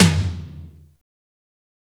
Index of /90_sSampleCDs/Roland - Rhythm Section/KIT_Drum Kits 6/KIT_Combo Kit
TOM BOOSH07L.wav